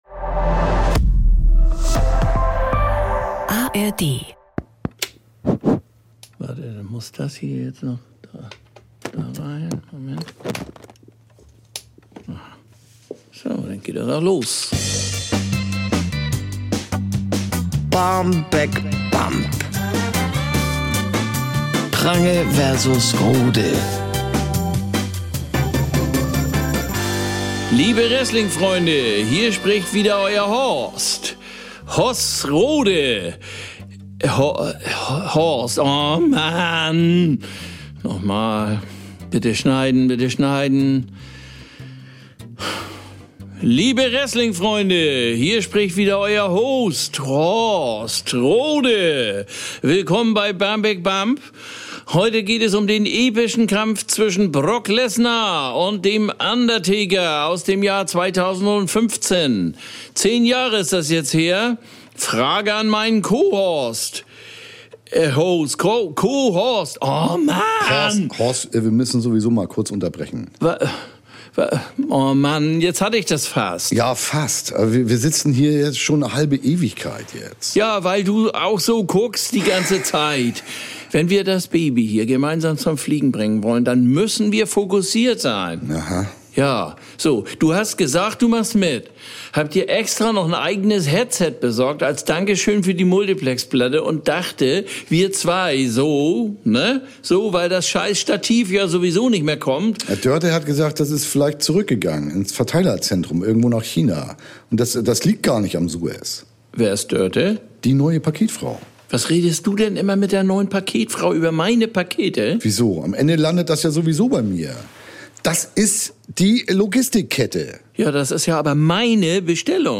Ralf Prange: Bjarne Mädel Horst Rohde: Olli Dittrich Sprecherin: Doris Kunstmann